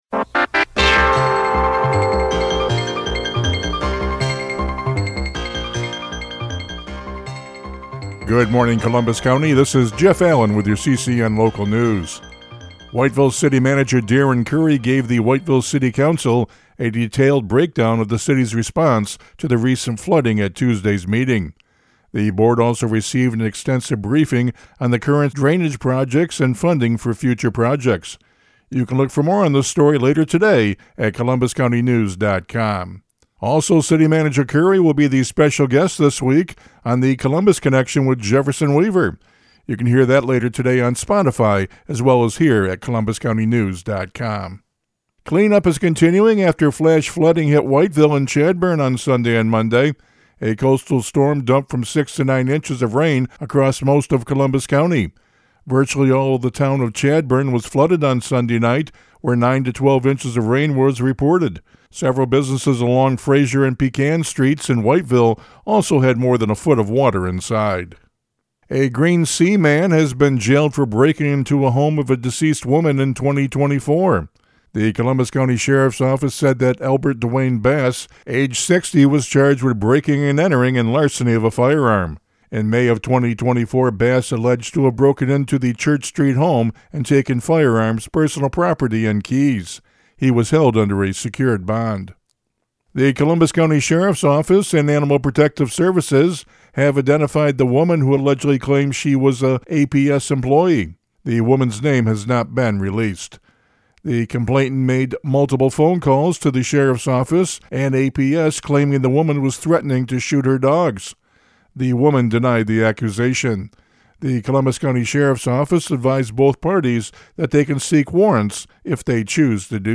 Front Page CCN Radio News — Morning Report for October 16, 2025
CCN Radio News – Morning Report for October 16, 2025 (Press Play for the Audio) 🔊